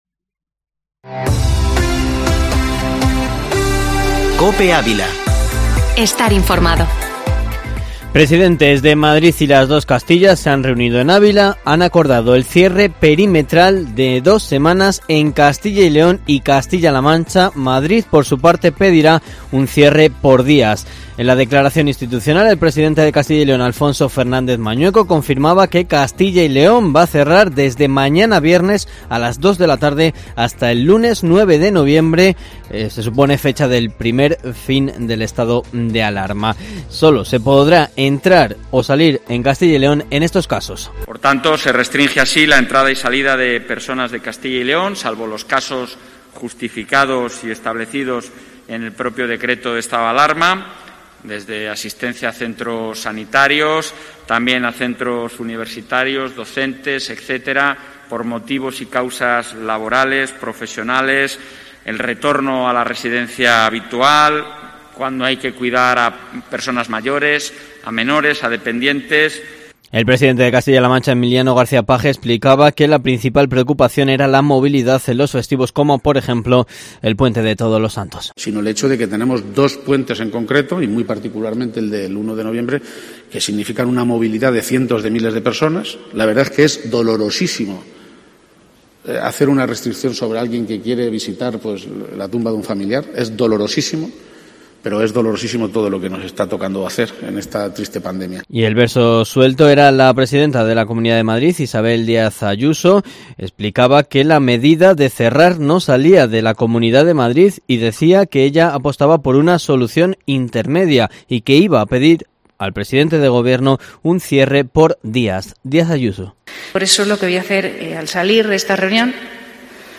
Informativo matinal Herrera en COPE Ávila 29/10/2020